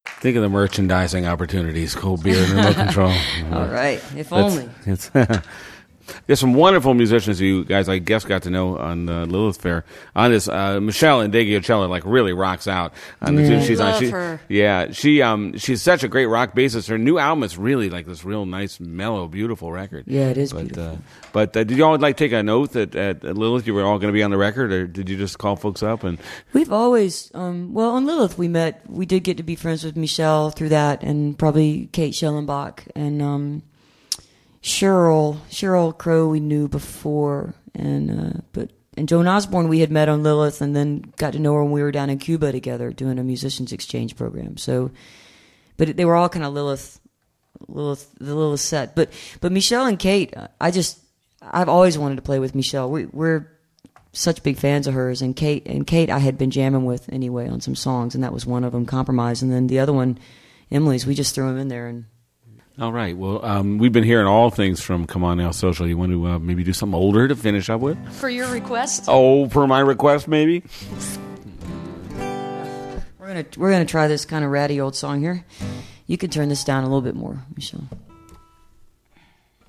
lifeblood: bootlegs: 1999-10-05: world cafe recording session at inderay studios - philadelphia, pennsylvania
09. interview (1:19)